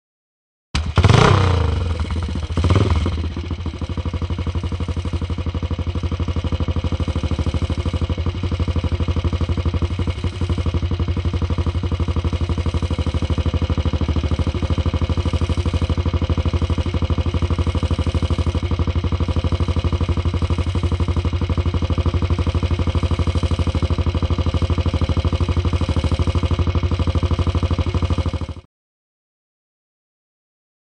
Motorcycle; Start / Idle; B.s.a. Single Kick Start And Idling.